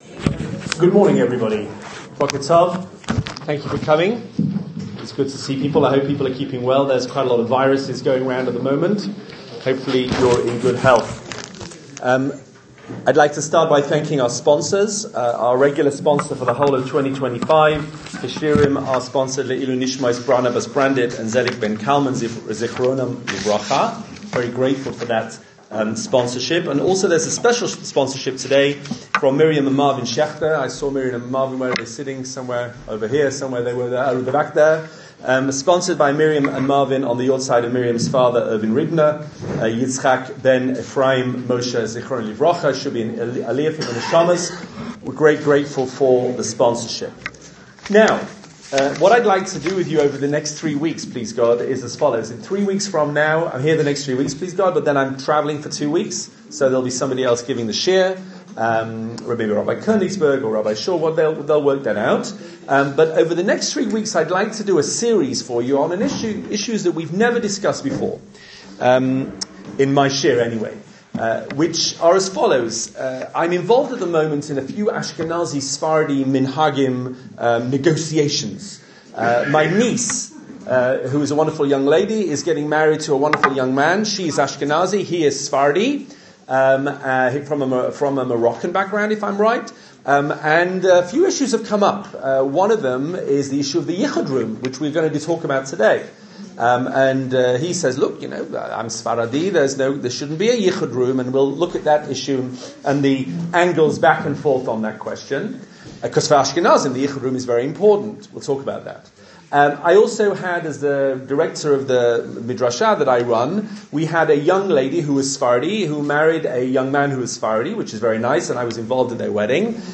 Ashkenazi & Sephardi Wedding Customs - a shiur